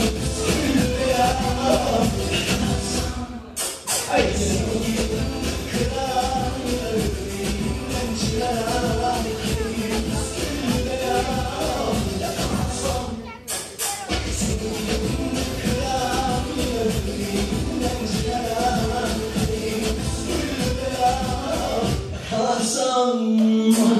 Pardon za kvalitu.